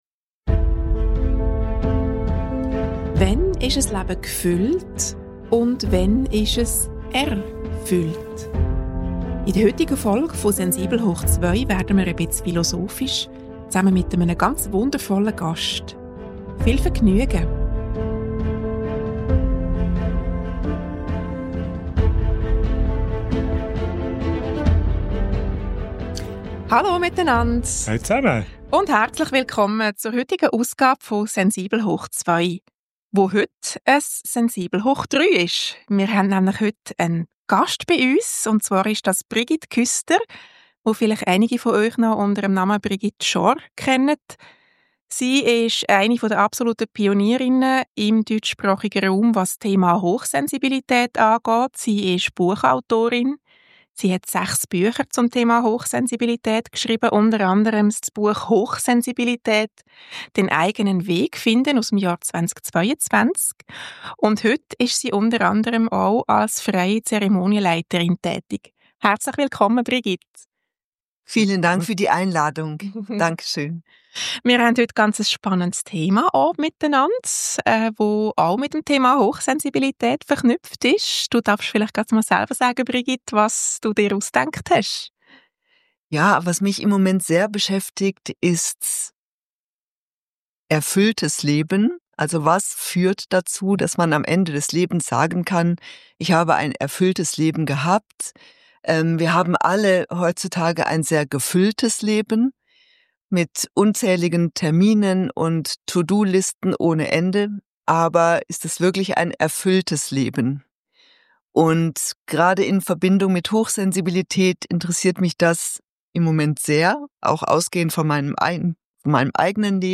In einem sehr heiteren, aber auch etwas philosophischen Gespräch tauschen wir uns darüber aus, wie aus etwas Banalem etwas Besonderes werden kann, wie es gelingt, dem Tag und jeder Begegnung mehr Tiefe zu verleihen – und wir widmen uns auch der Endlichkeit des eigenen Seins.